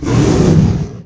hurt2.wav